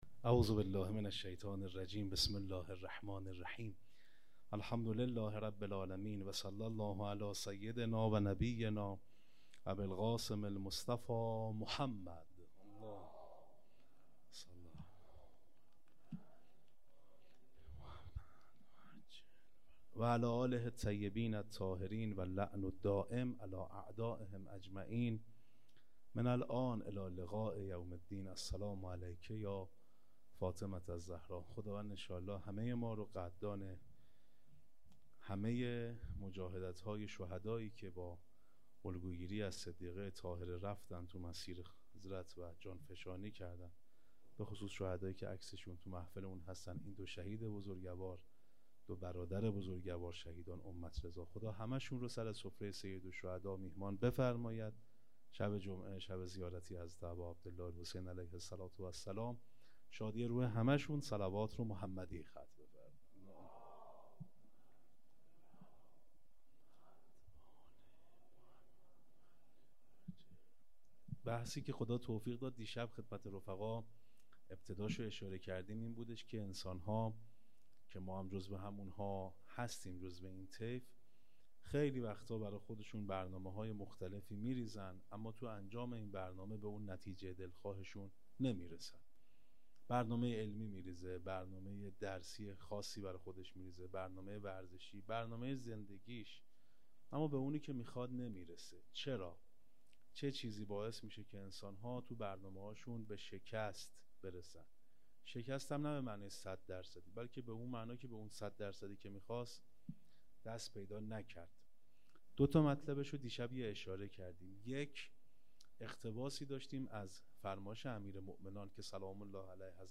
سخنرانی ( ادامه مبحث نظم و برنامه ریزی